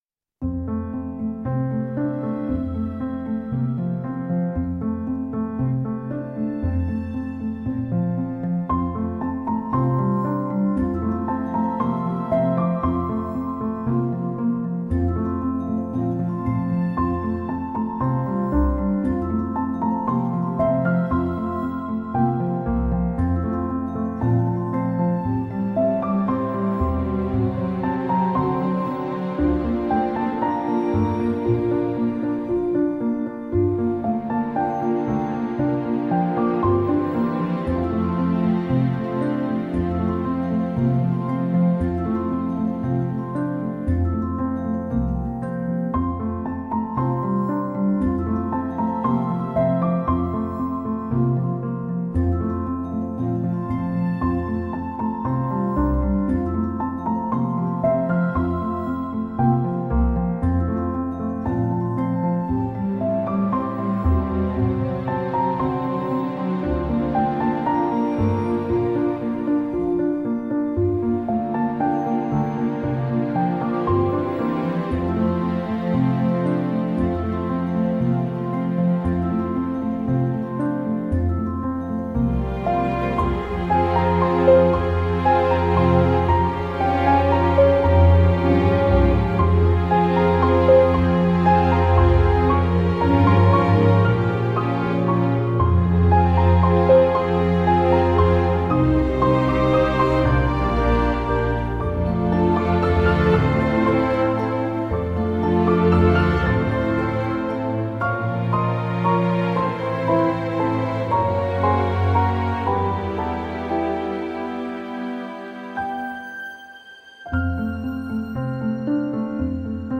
pour piano et cordes
Discrète mais lyrique.